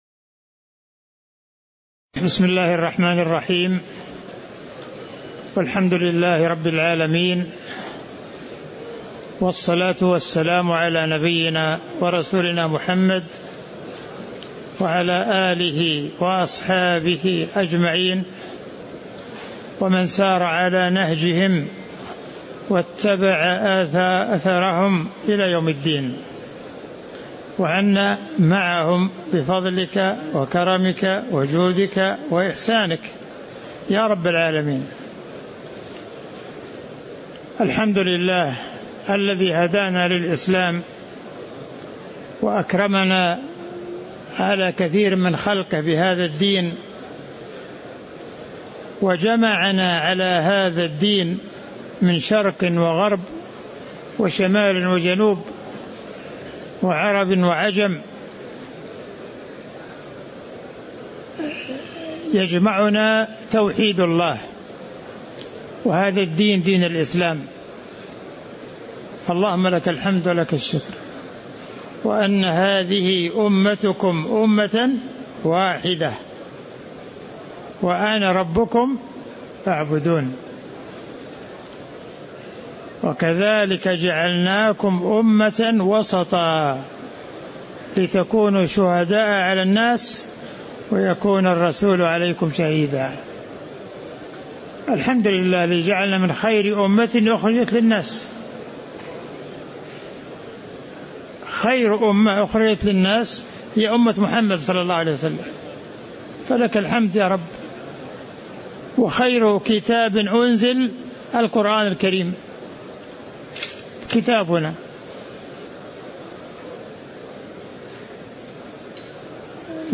تاريخ النشر ١٩ رمضان ١٤٤٥ هـ المكان: المسجد النبوي الشيخ